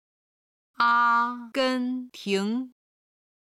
阿根廷　(Ā gēn tíng)　アルゼンチン